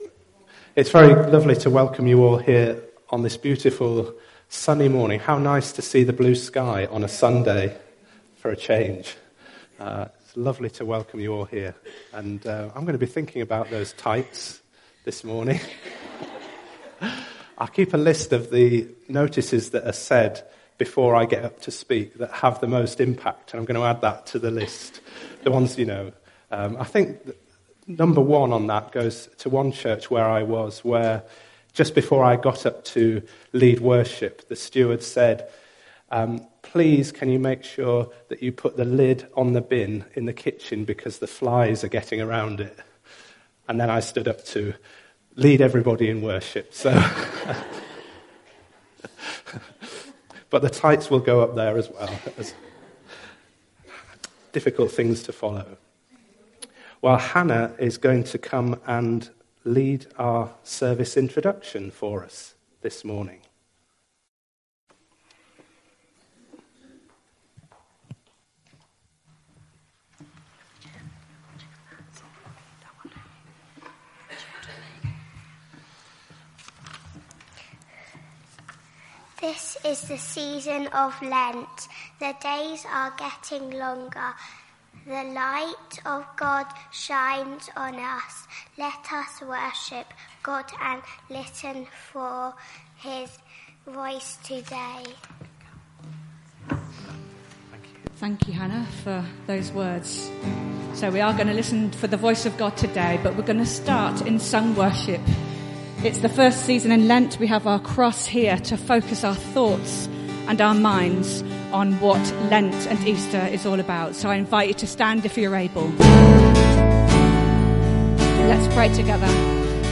The songs were: Lord I lift your name on high; Who you say I am; Seek ye first the kingdom of God (1st 3 verses); Cast your burdens; Lord you have my heart; I will set my face to seek the Lord.
Service Type: All Age Worship